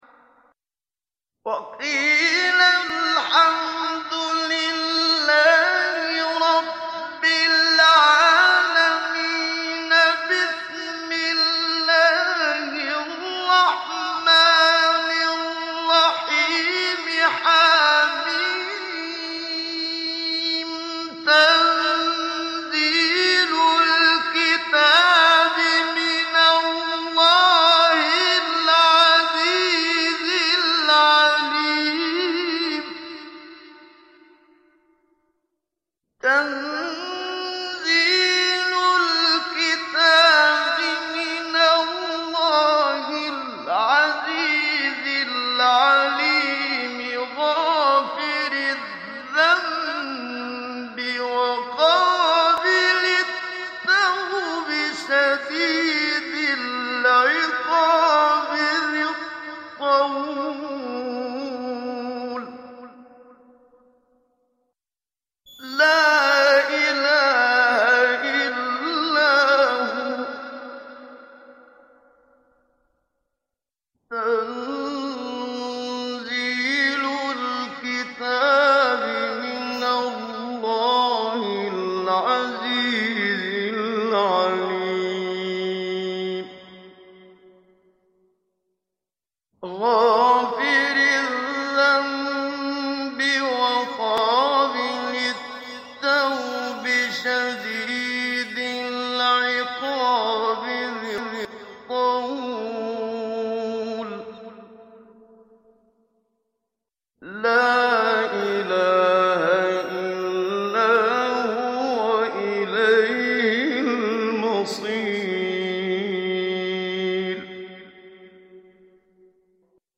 Sourate Ghafir Télécharger mp3 Muhammad Siddiq Minshawi Mujawwad Riwayat Hafs an Assim, Téléchargez le Coran et écoutez les liens directs complets mp3
Télécharger Sourate Ghafir Muhammad Siddiq Minshawi Mujawwad